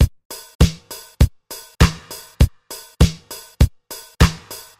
描述：低音迪斯科舞曲，在一个舞曲上应用（两次）LP滤波器 100Hz。
标签： 低音的 舞蹈 迪斯科 音乐 派对 电影 影片
声道立体声